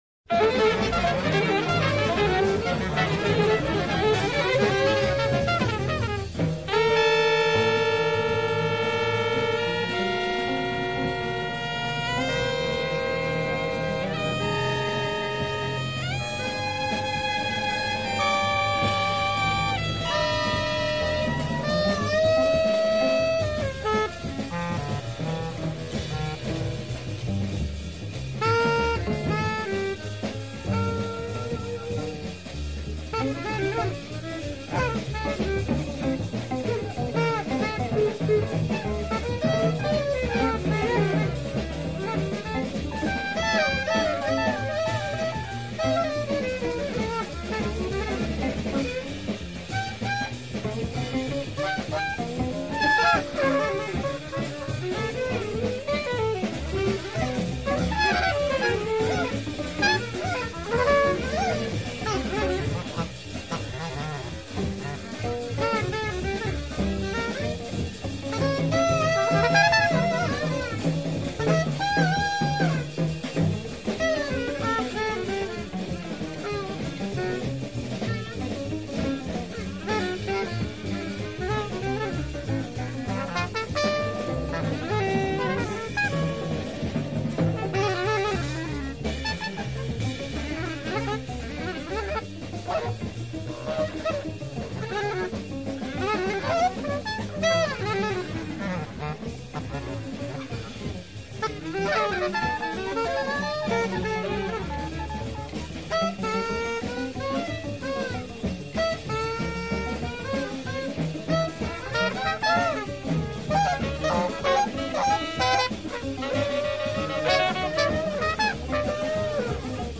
Musique sans ligne mélodique,
improvisation sans cohésion,
Le saxo s’égare,
la batterie s’excite,
dans une jolie cacophonie.
De la musique atonale,
dans des rythmes  incohérents.